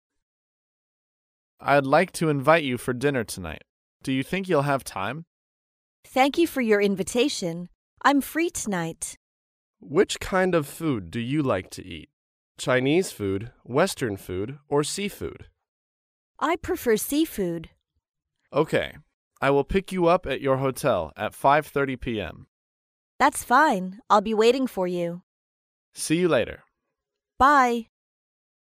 在线英语听力室高频英语口语对话 第41期:邀约共进晚餐(2)的听力文件下载,《高频英语口语对话》栏目包含了日常生活中经常使用的英语情景对话，是学习英语口语，能够帮助英语爱好者在听英语对话的过程中，积累英语口语习语知识，提高英语听说水平，并通过栏目中的中英文字幕和音频MP3文件，提高英语语感。